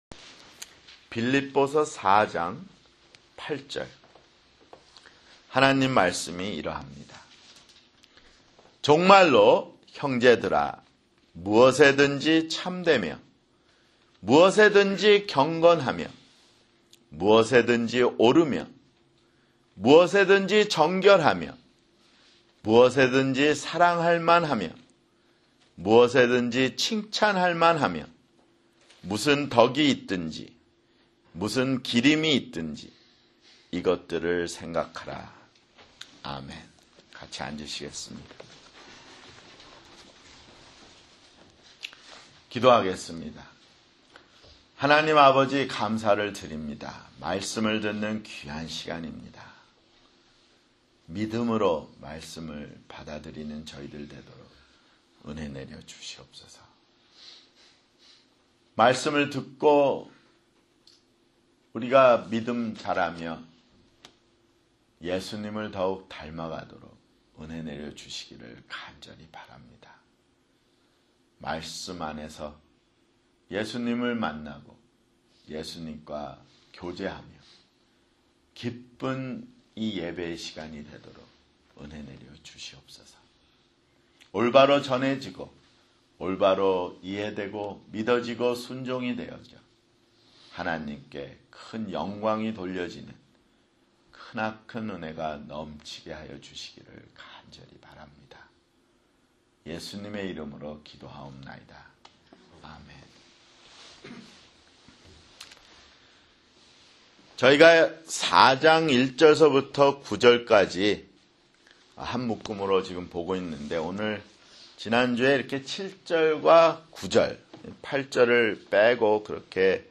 [주일설교] 빌립보서 (58)